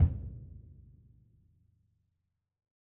BDrumNewhit_v4_rr2_Sum.wav